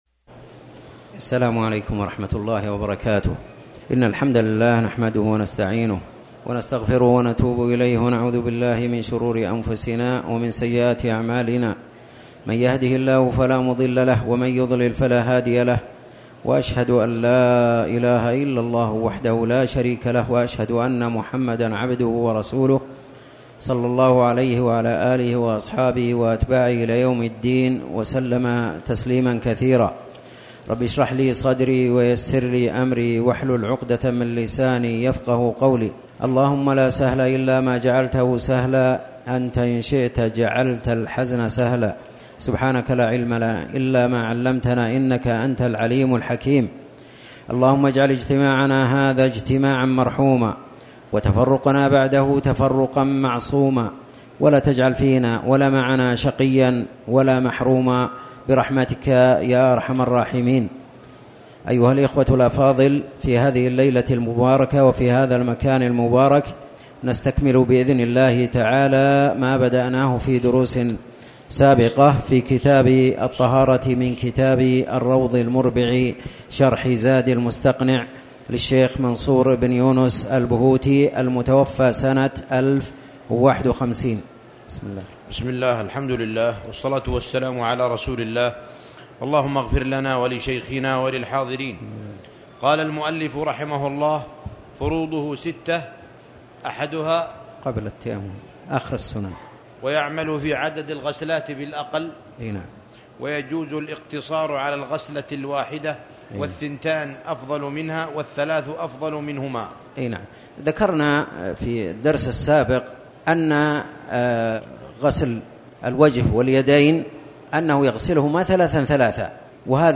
تاريخ النشر ٩ رجب ١٤٣٩ هـ المكان: المسجد الحرام الشيخ